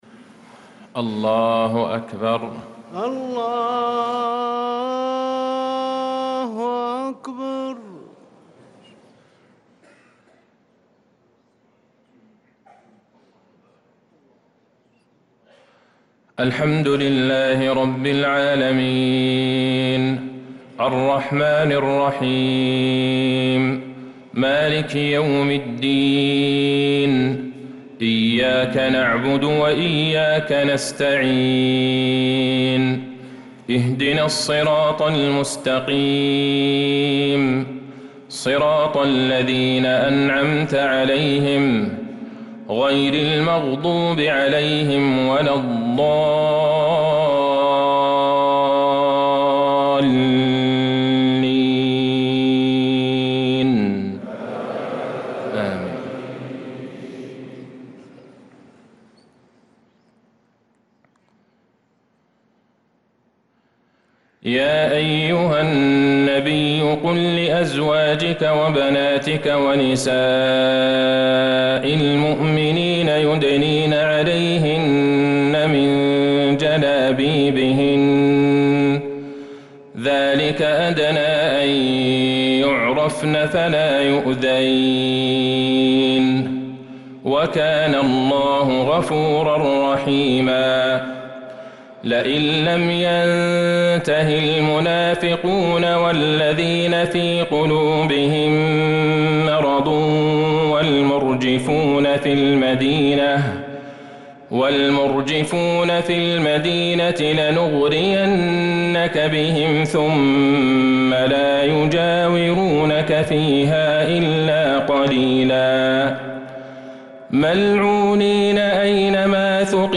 صلاة العشاء للقارئ عبدالله البعيجان 19 ربيع الآخر 1446 هـ
تِلَاوَات الْحَرَمَيْن .